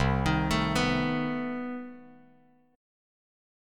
CmM13 chord